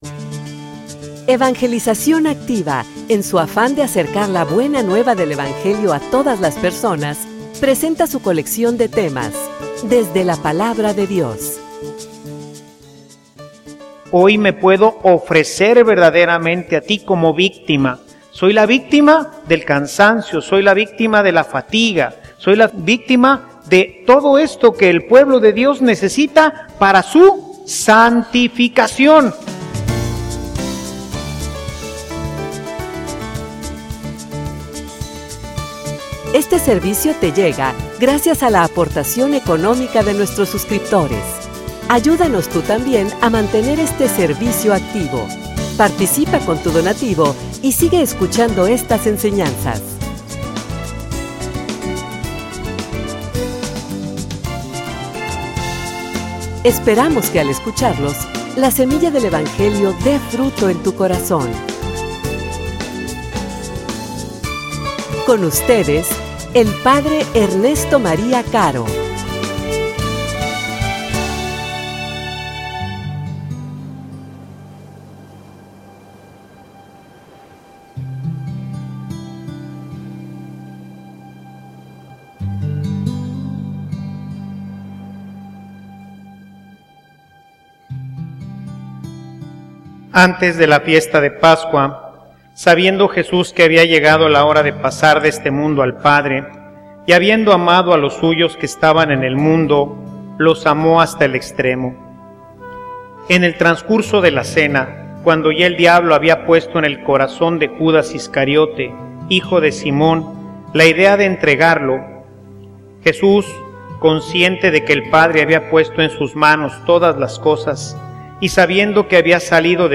homilia_El_legado_de_Cristo.mp3